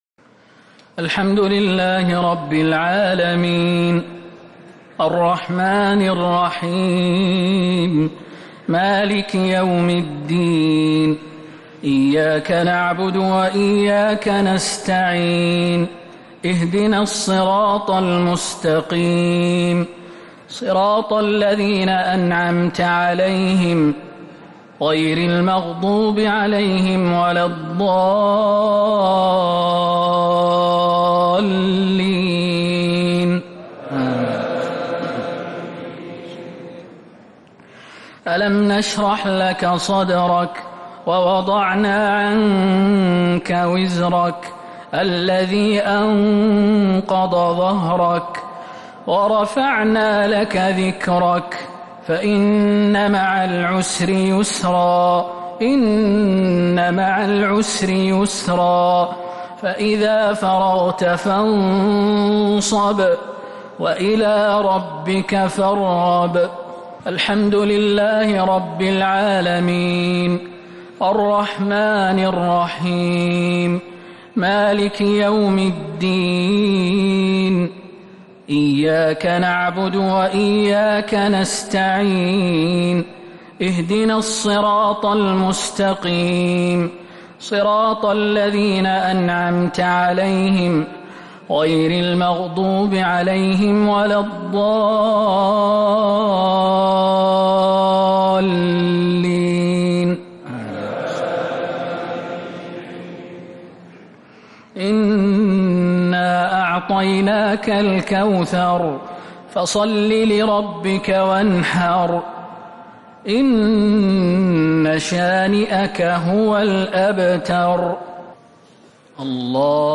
صلاة الشفع و الوتر ليلة 7 رمضان 1447هـ | Witr 7th night Ramadan 1447H > تراويح الحرم النبوي عام 1447 🕌 > التراويح - تلاوات الحرمين